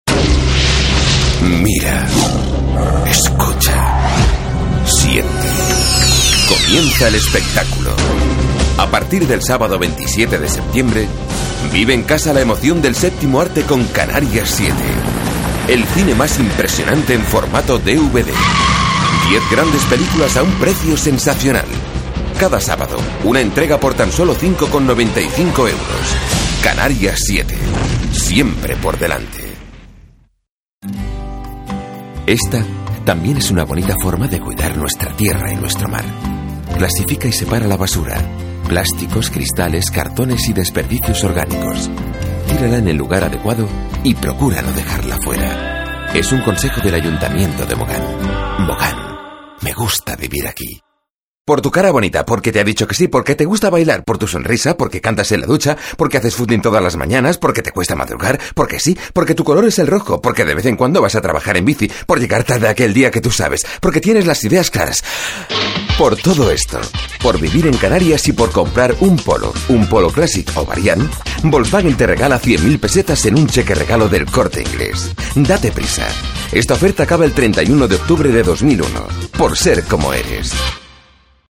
Voces Masculinas